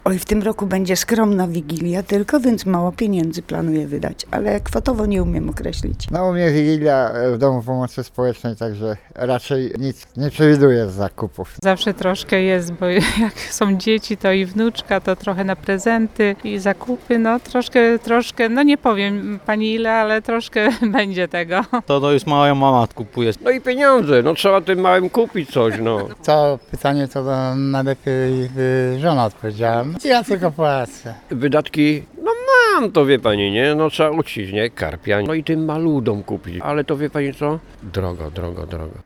mieszkancy_ile_na_swieta.mp3